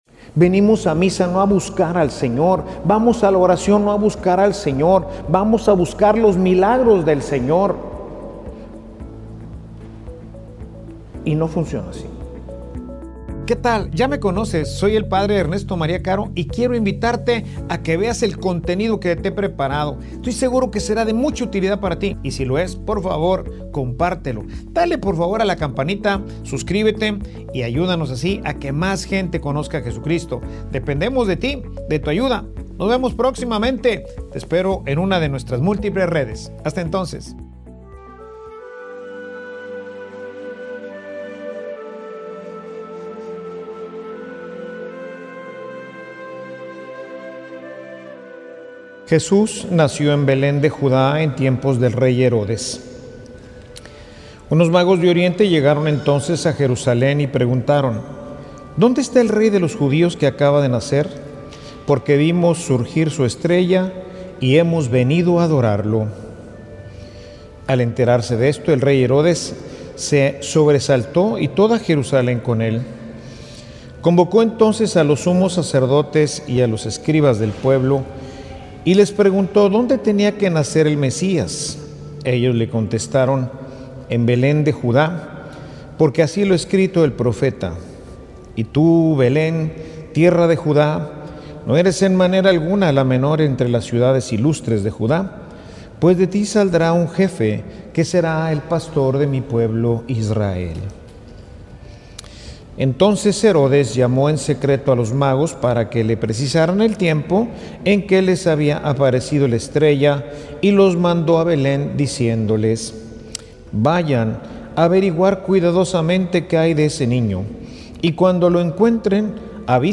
Homilia_La_curiosidad_que_te_lleva_a_jesus.mp3